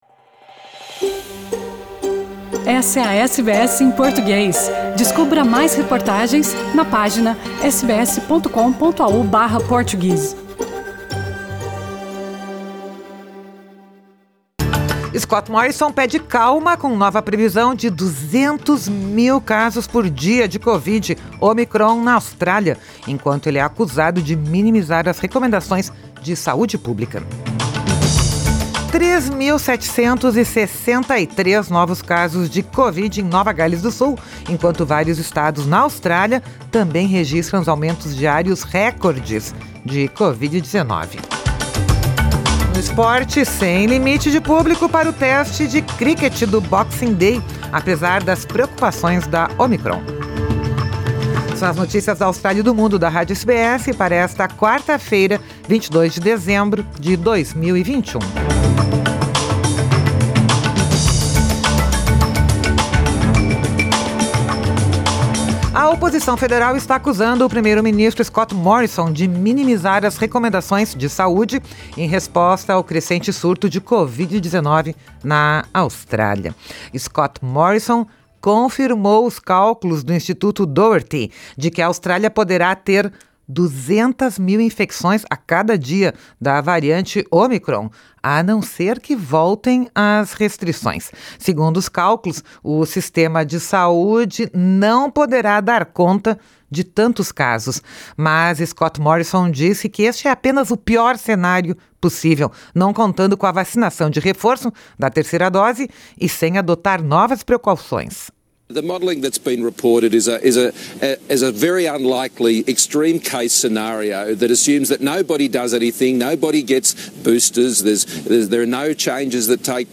Scott Morrison pede calma com nova previsão de 200 mil casos por dia da variante ômicron da Covid-19 na Austrália, enquanto é acusado de minimizar as recomendações de saúde pública. 3.763 novos casos de Covid-19 em Nova Gales do Sul enquanto vários estados na Austrália também registram aumentos diários recordes de Covid-19. E no esporte, sem limite de público para o Teste de Críquete do Boxing Day, apesar das preocupações da ômicron. São as notícias da Austrália e do Mundo da Rádio SBS para esta quarta-feira, 22 de dezembro de 2021.